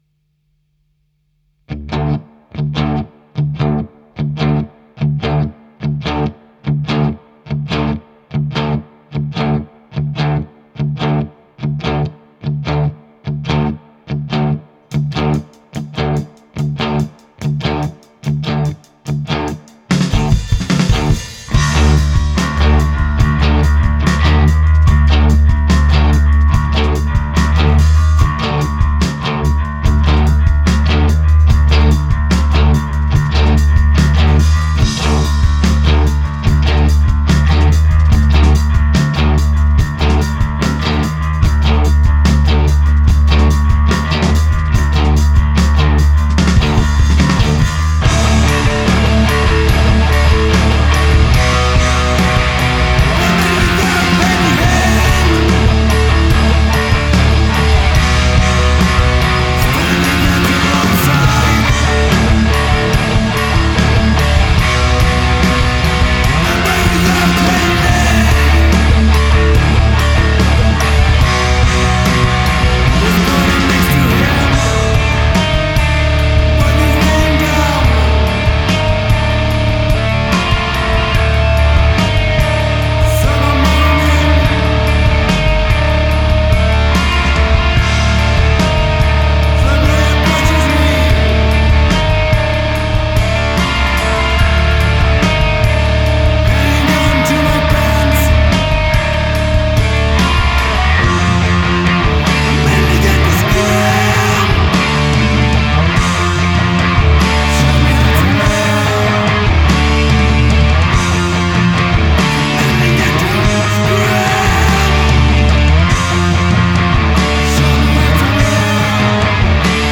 Brutale et directe
à savoir un rock sauvage et noisy